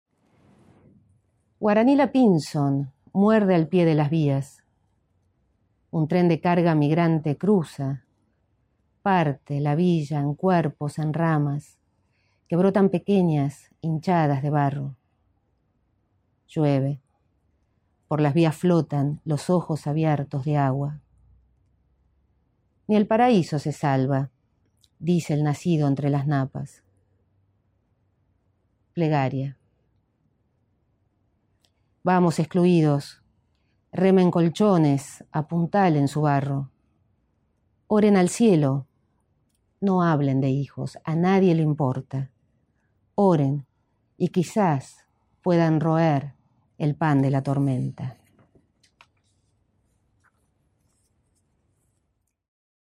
Selección de poemas III
La voz de la poeta despierta, denuncia hasta el horror de la plegaria.